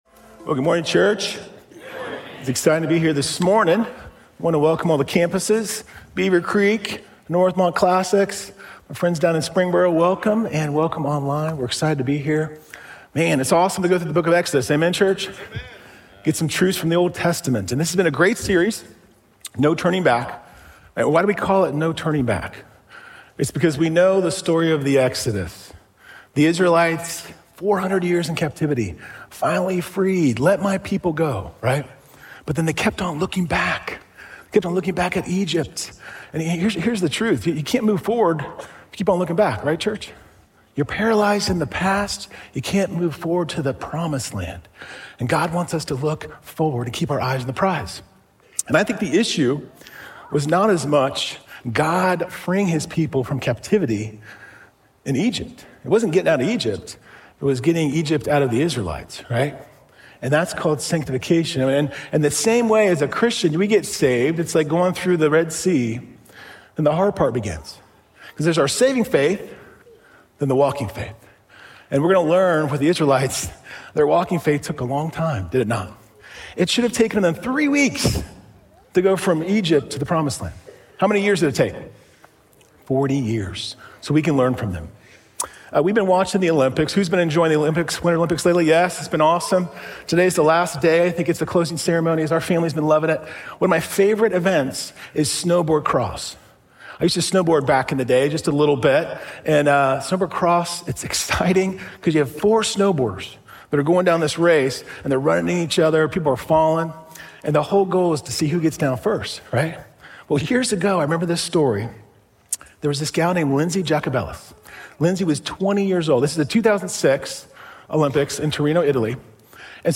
The-Cure-for-Idols_SERMON.mp3